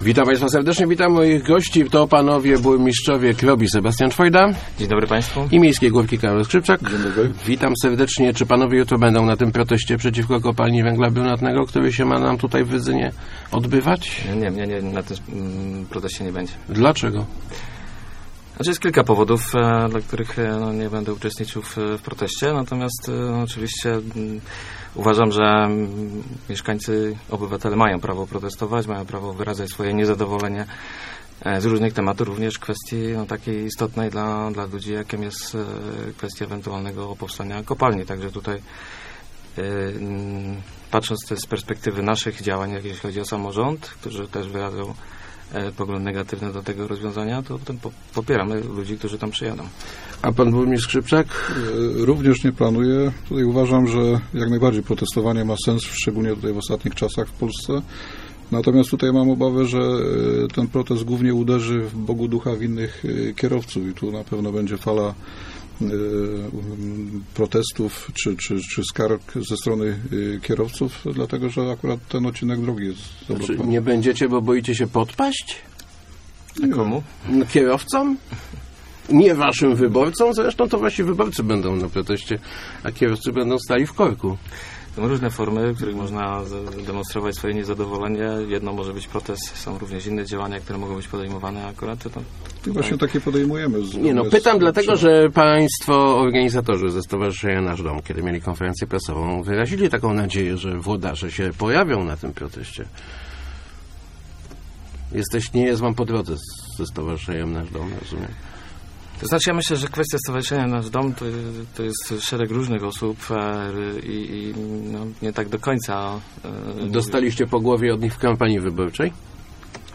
Plany zagospodarowania przestrzennego nie przewidują możliwości ulokowania u nas kopalni węgla brunatnego - mówili w Rozmowach Elki burmistrzowie Krobi i Miejskiej Górki Sebastian Czwojda i Karol Skrzypczak.